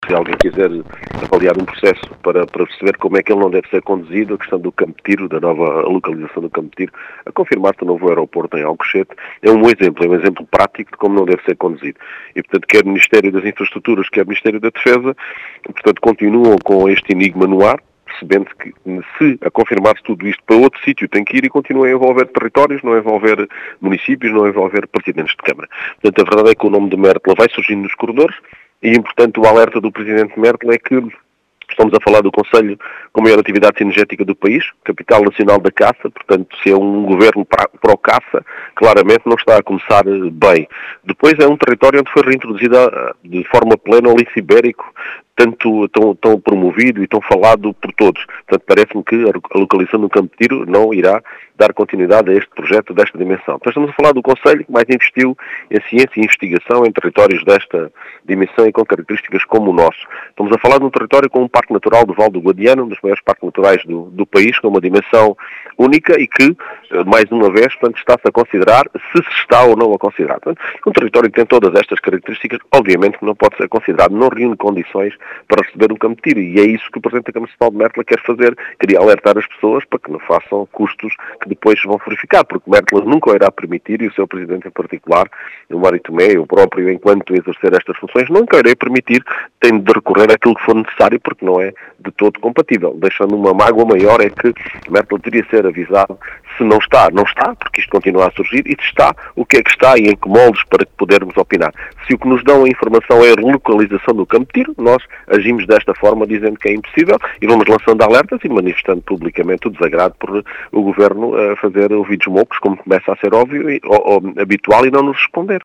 As explicações são do presidente da Câmara de Mértola, Mário Tomé, que deixa várias criticas ao Governo, na condução deste projeto, que diz “não ser compatível” com este território.